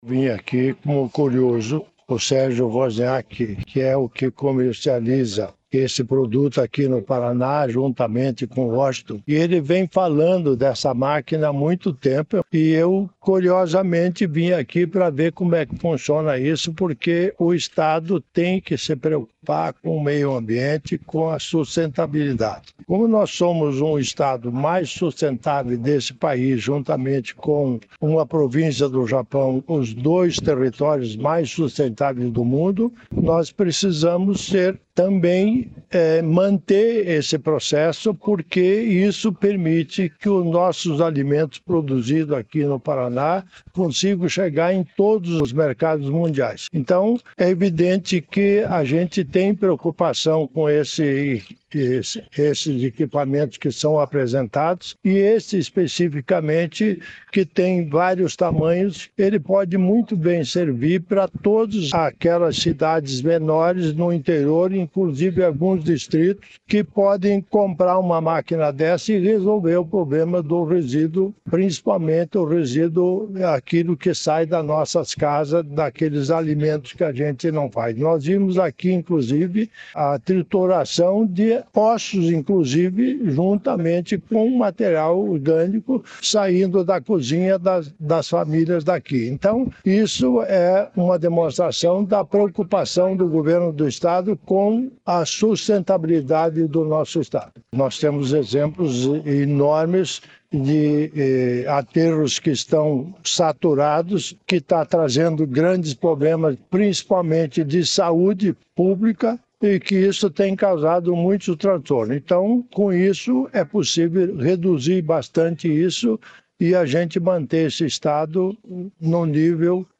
Sonora do governador em exercício, Darci Piana, sobre a máquina de compostagem acelerada